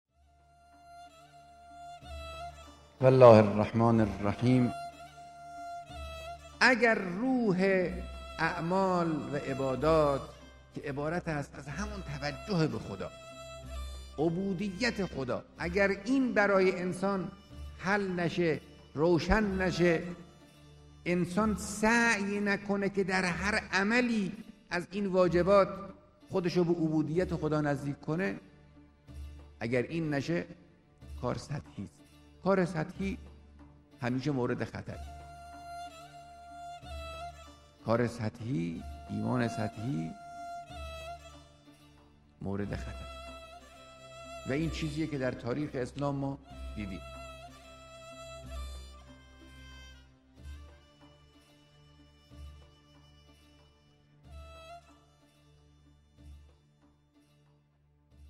صوت رهبر انقلاب اسلامی